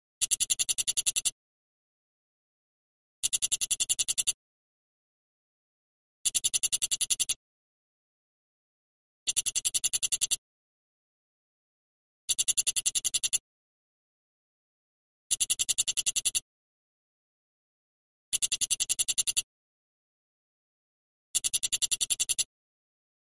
Heuschrecke klingelton kostenlos
Kategorien: Tierstimmen